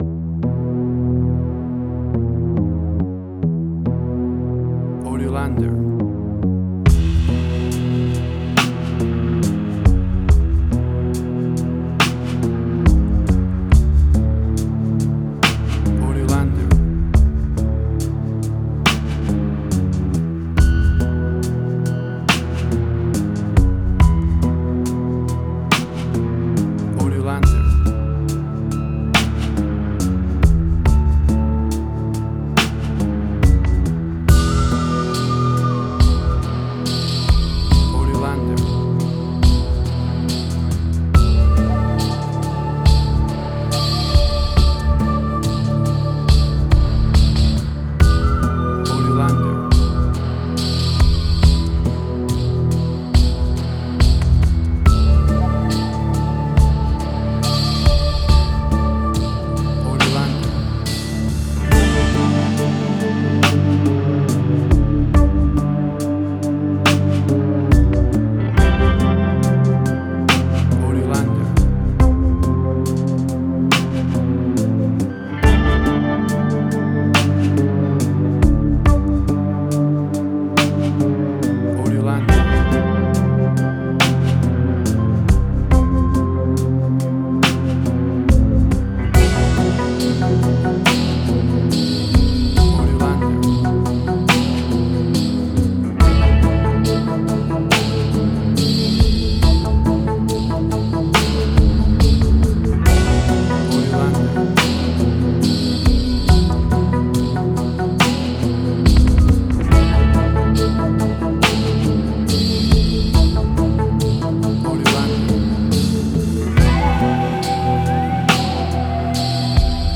Chill Out.
Tempo (BPM): 70